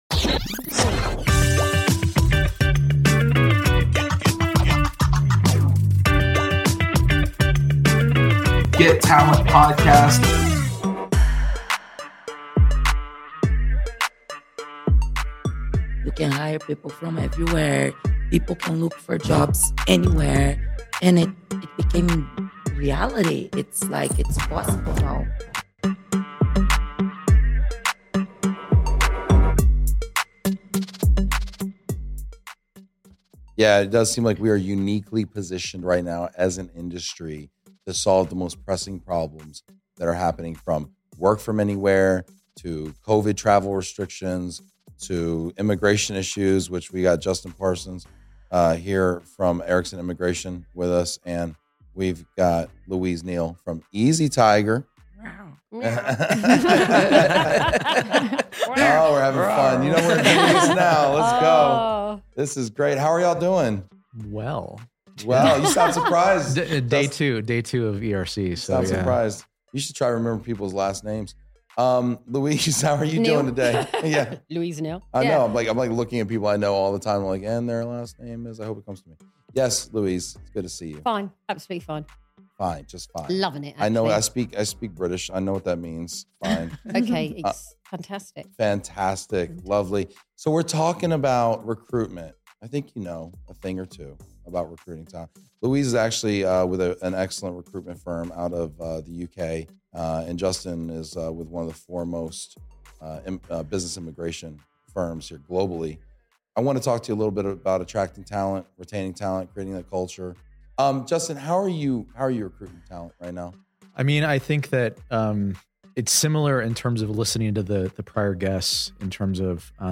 GET TALENT! | #19 | LIVE FROM BLUE WIRE STUDIO